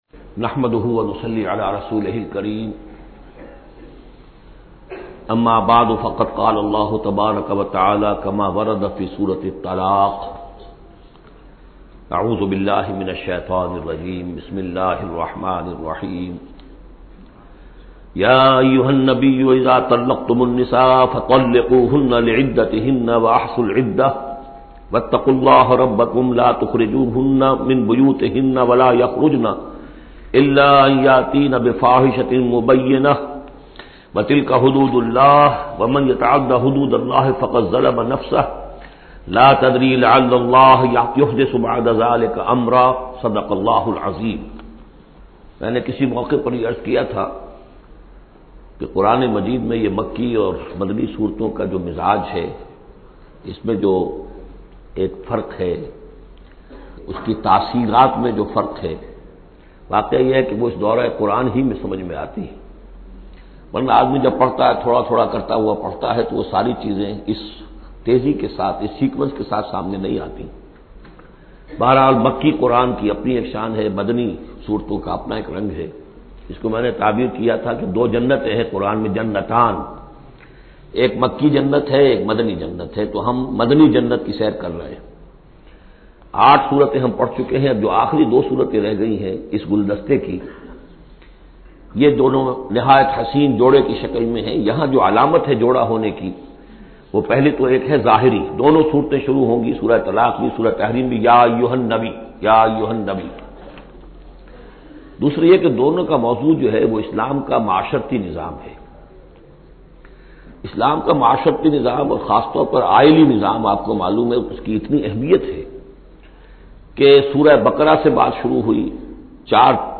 Listen online mp3 urdu tafseer of Surah Talaq in the voice of Dr Israr Ahmed. Download audio tafseer free mp3 in best audio quality.